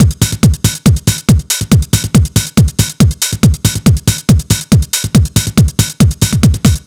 NRG 4 On The Floor 007.wav